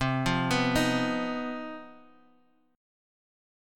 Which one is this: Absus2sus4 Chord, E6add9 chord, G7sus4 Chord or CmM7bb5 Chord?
CmM7bb5 Chord